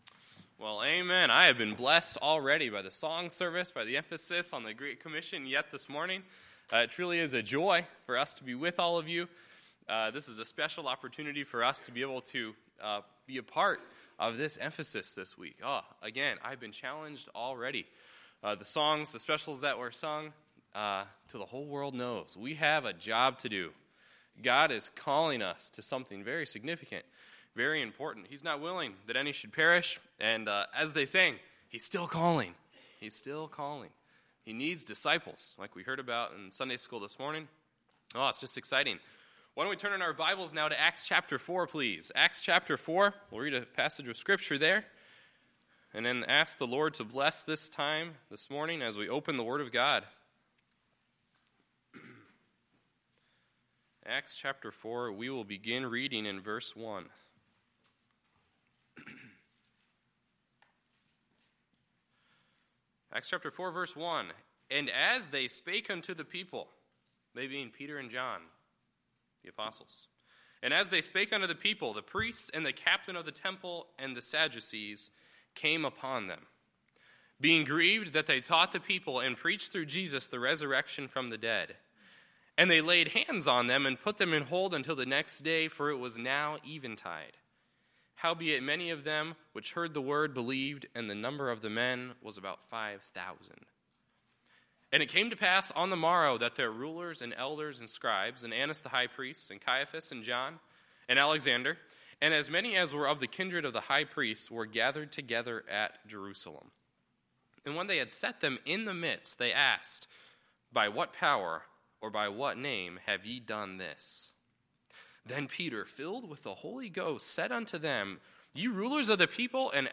Sunday, September 24, 2017 – Missions Conference Sunday AM Service
Sermons